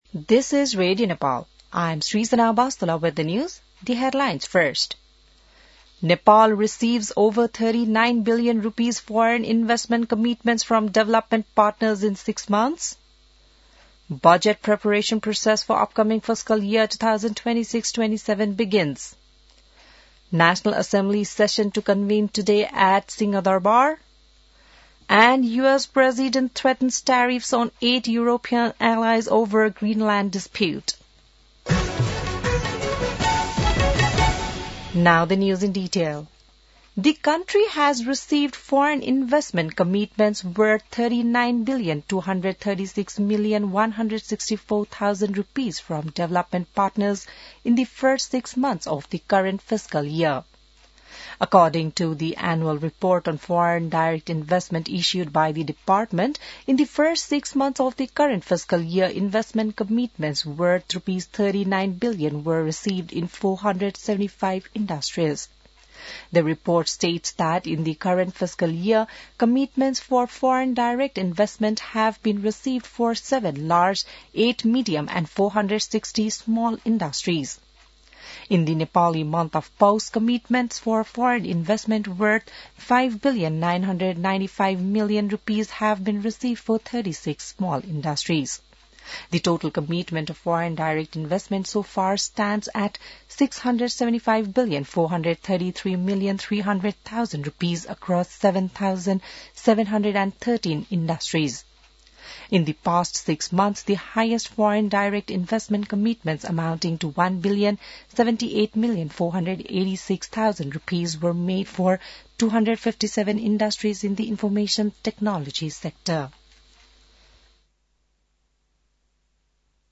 बिहान ८ बजेको अङ्ग्रेजी समाचार : ४ माघ , २०८२